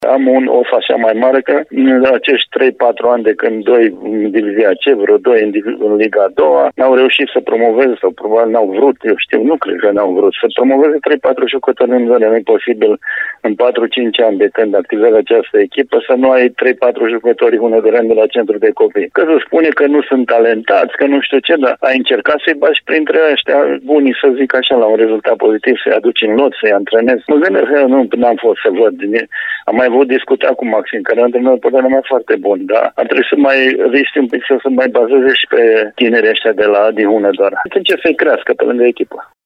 Romulus Gabor – acționați butonul „Redare” pentru declarația în format AUDIO